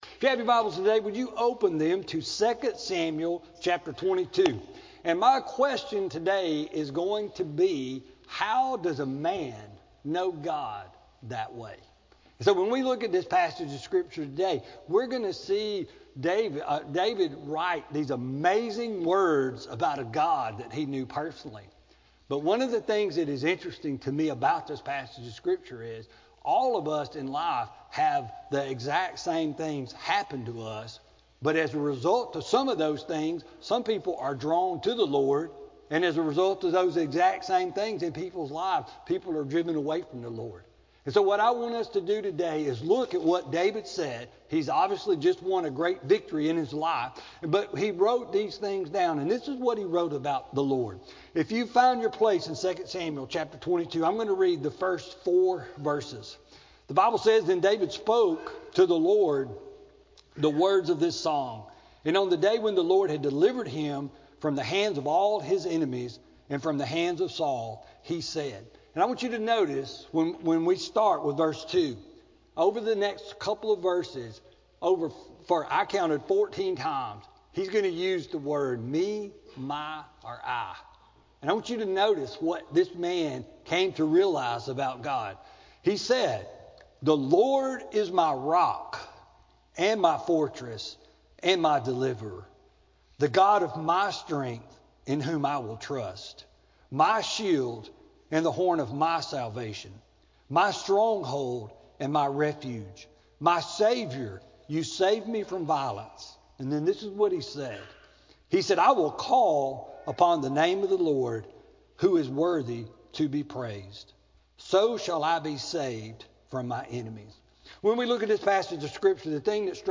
Sermon-2-24-19-CD.mp3